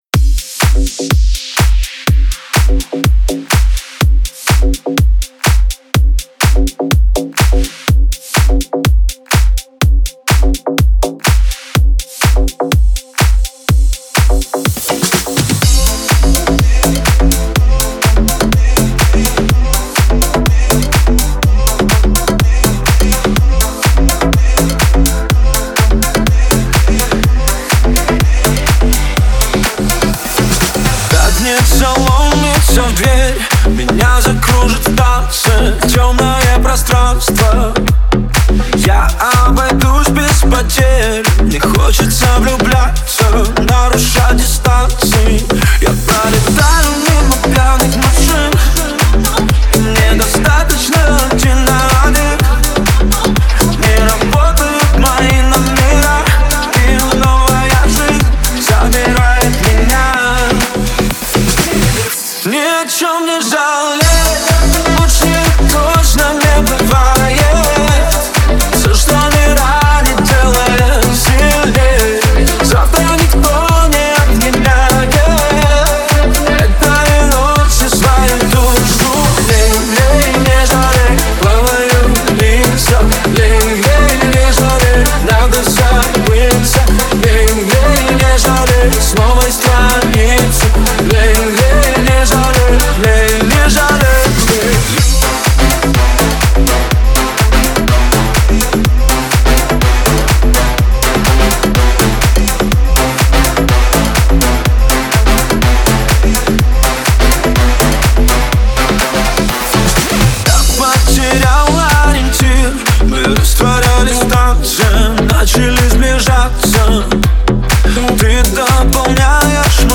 радует слушателей своим харизматичным вокалом